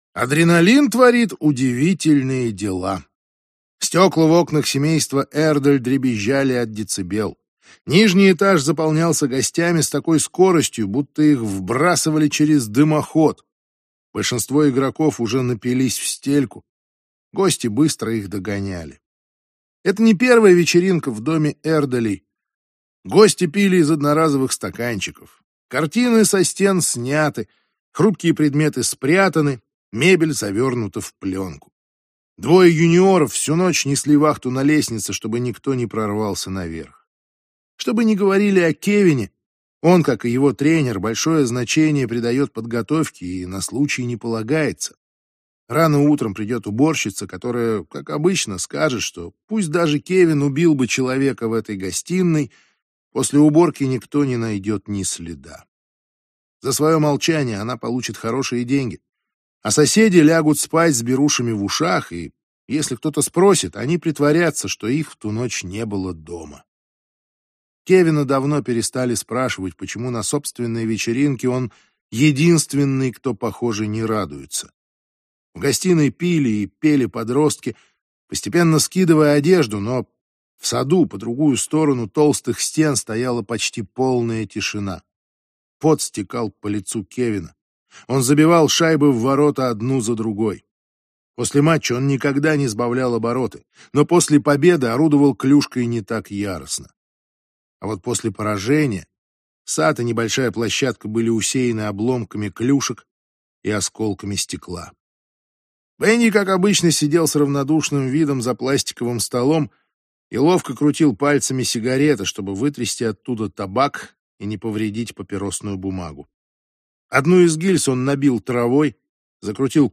Аудиокнига Медвежий угол - купить, скачать и слушать онлайн | КнигоПоиск